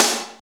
46.02 SNR.wav